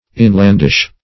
inlandish - definition of inlandish - synonyms, pronunciation, spelling from Free Dictionary Search Result for " inlandish" : The Collaborative International Dictionary of English v.0.48: Inlandish \In"land*ish\, a. Inland.